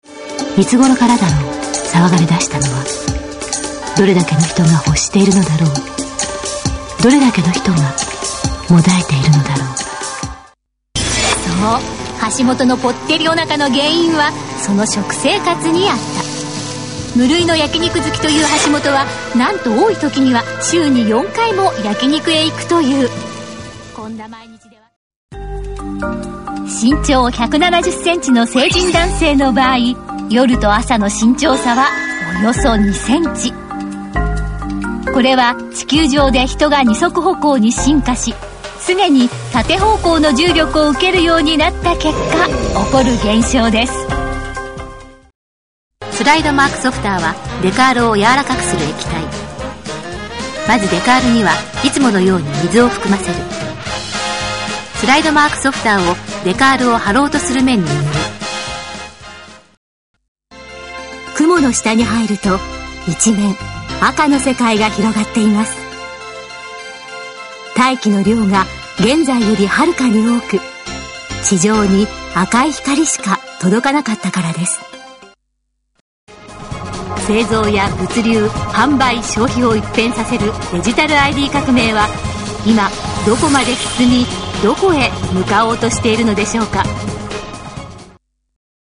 TVナレーション-低め落ち着き　TVナレーション
《低め系落ち着き系》   1 少しセクシーな低めのナレーション
3 軽さも併せ持ちながら、落ち着いたナレーション
4 感情を極力抑えた機械的ナレーション
5 重すぎず軽すぎずのドキュメントタッチ
6 きっちり押さえこむ経済ナレーション